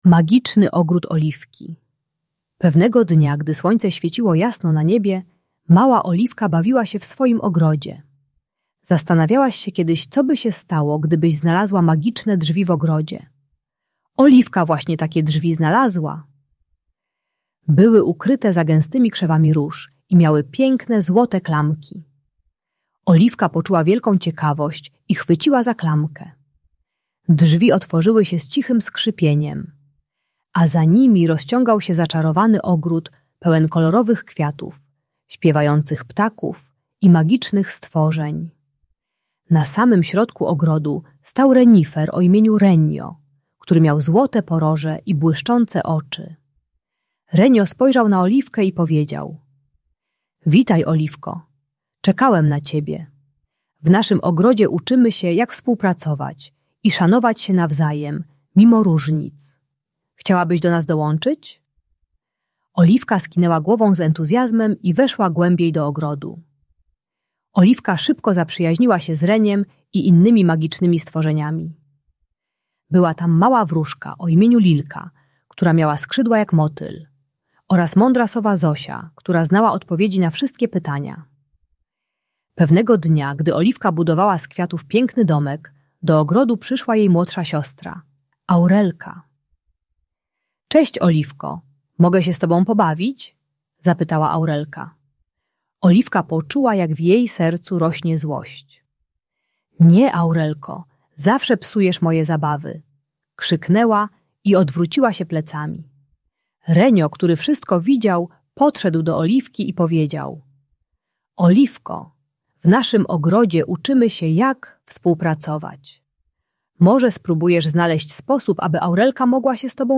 Magiczny Ogród Oliwki - Rodzeństwo | Audiobajka